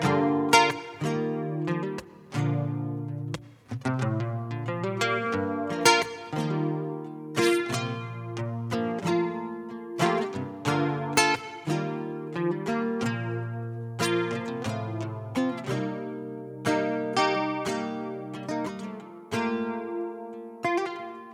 Dadurch wird eine räumliche Verbreitung simuliert.
Chorus.wav